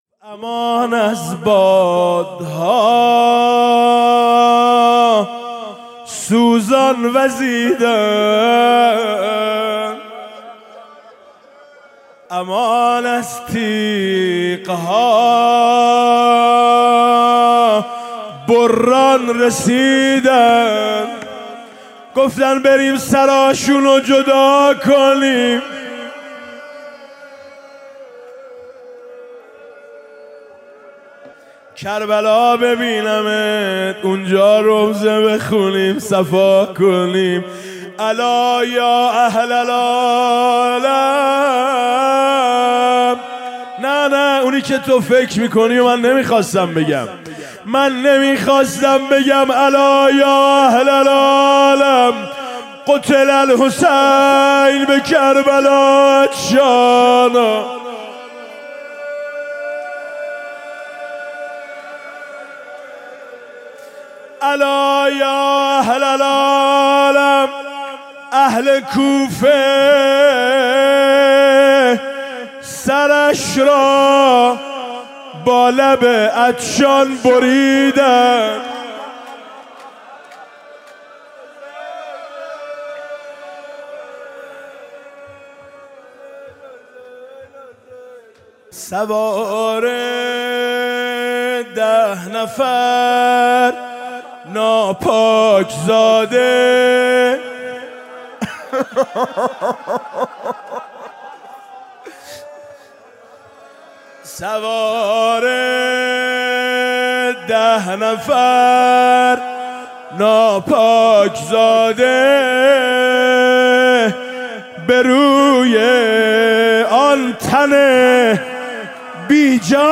شب دوازدهم رمضان 96 - هیئت شهدای گمنام - روضه - سرش را با لب عطشان بریدند
روضه